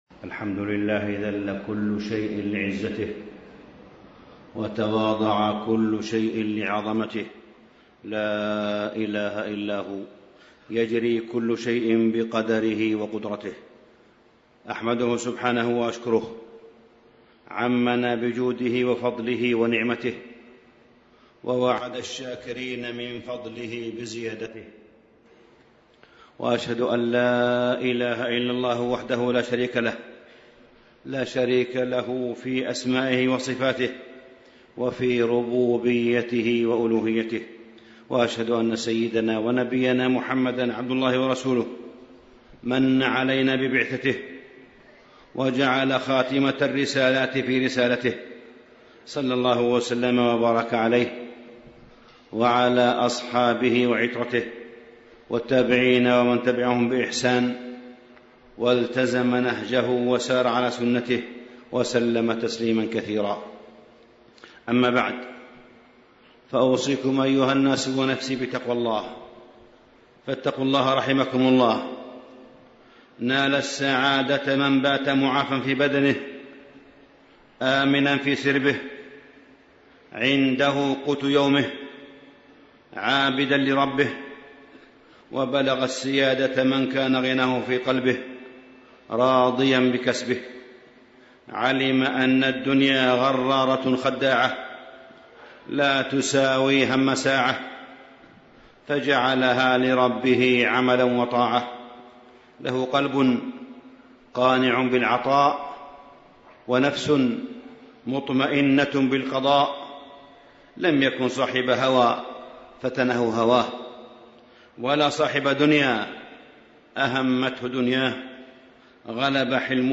تاريخ النشر ٢٣ ربيع الأول ١٤٣٥ هـ المكان: المسجد الحرام الشيخ: معالي الشيخ أ.د. صالح بن عبدالله بن حميد معالي الشيخ أ.د. صالح بن عبدالله بن حميد الحياة الإيمانية والحياة المادية